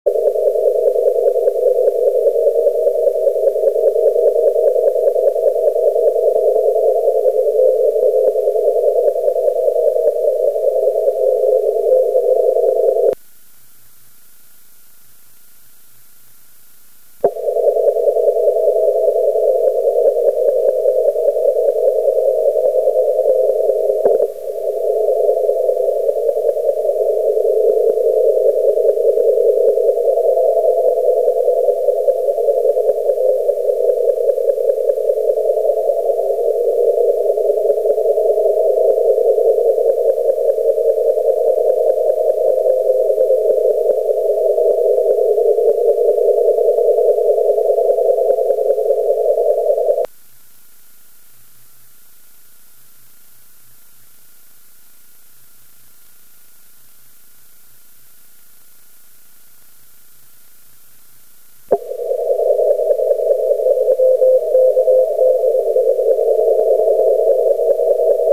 コンテスト中に録音したオーディオファイルを少し整理してみました。
またリグのLine-out端子にはサイドトーンが出てこないようで、小生が送信してる部分は無音状態になってしまってます。